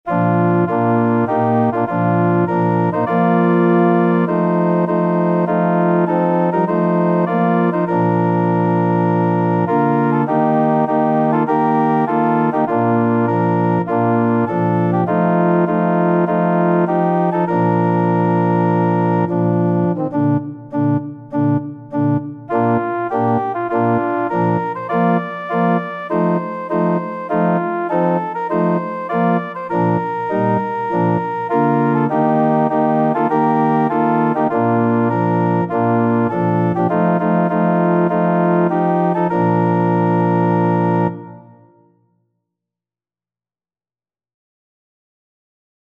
Christian
4/4 (View more 4/4 Music)
Organ  (View more Easy Organ Music)
Classical (View more Classical Organ Music)